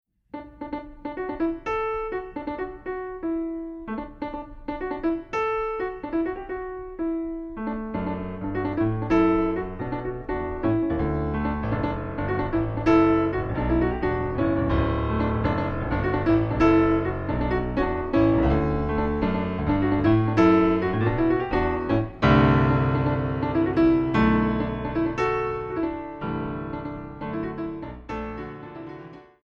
Solo Piano Concert
Recording: Ralston Hall, Santa Barbara, CA, January, 2008
Piano